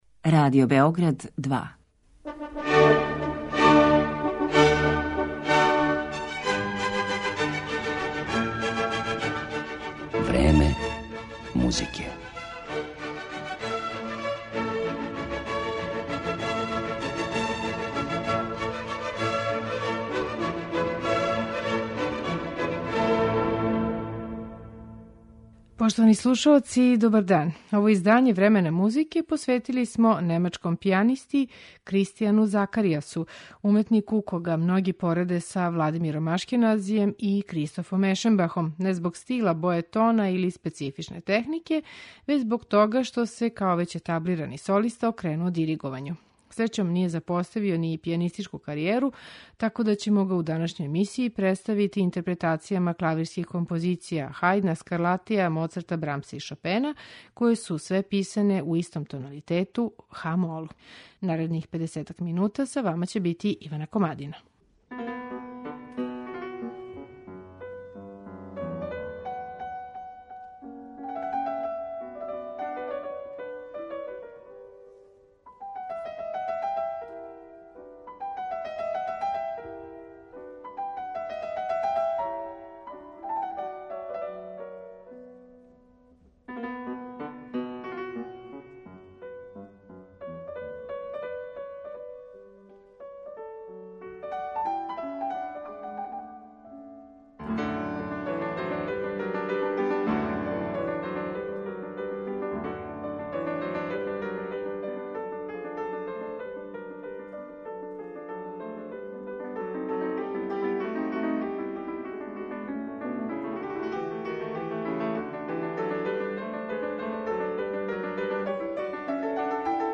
KРИСТИЈАН ЗАKАРИЈАС, клавир
Kуриозитет овог програма крије се у чињеници да су све композицијекоје су се на њему нашле писане у једном тоналитету - хa-молу.